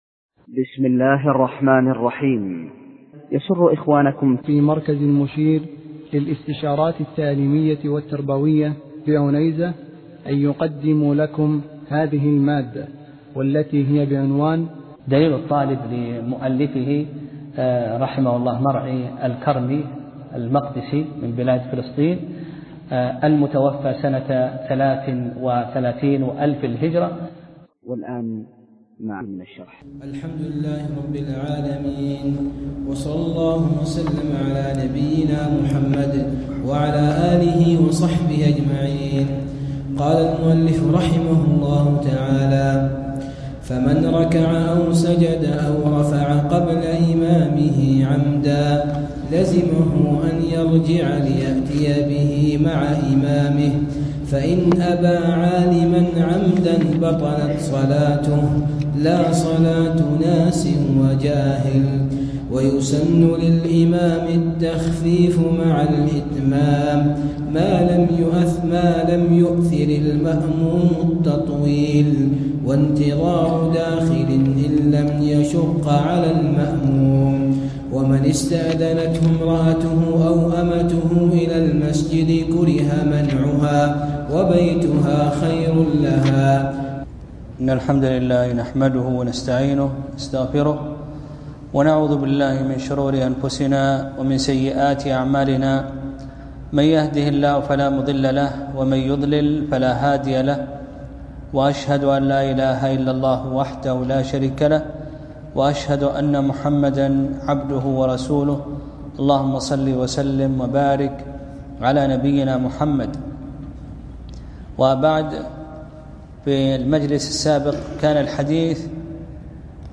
درس (11) : فصل في الإمامة